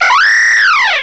cry_not_hawlucha.aif